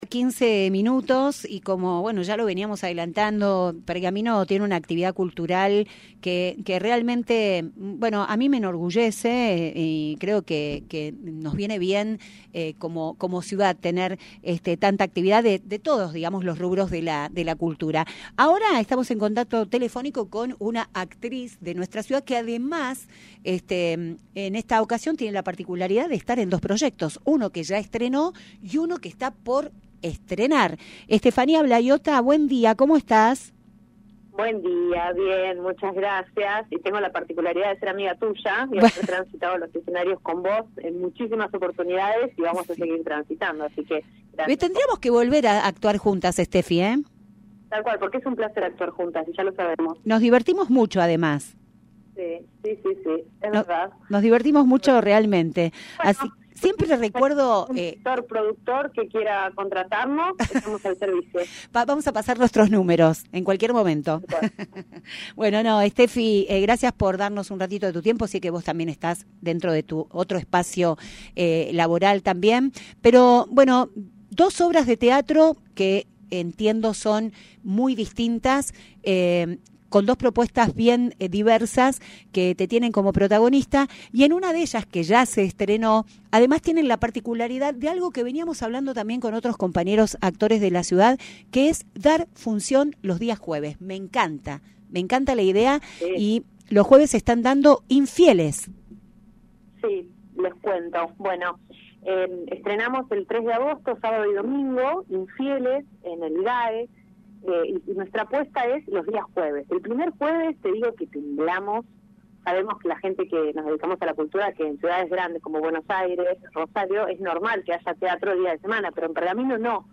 Durante una entrevista realizada en el programa «La Mañana de la Radio» por Radio Mon Pergamino,